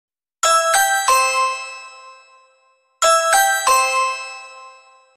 Kategori Ses Efektleri